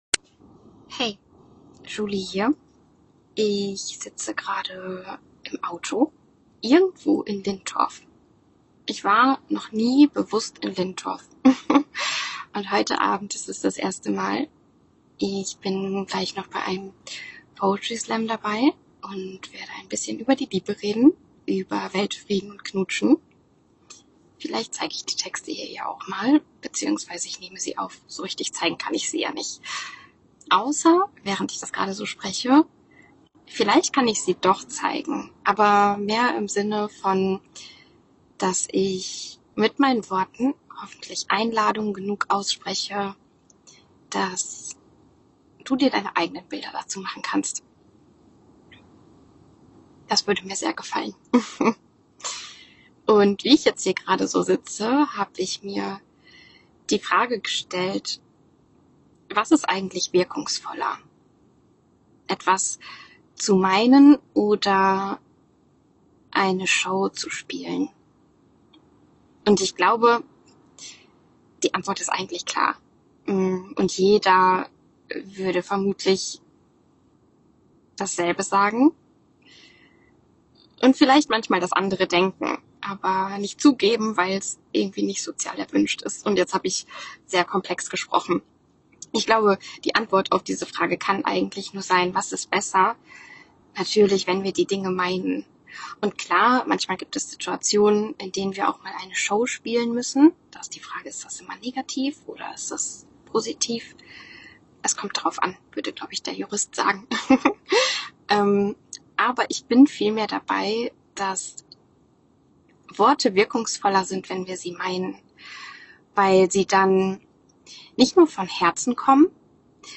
Diese Folge ist mitten auf einem Parkplatz in Lintorf entstanden.
Man hört, glaube ich, auch die Geräusche im Auto.